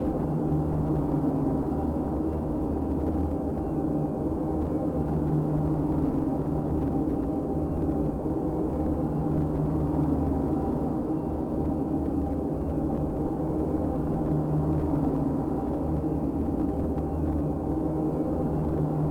volcano.ogg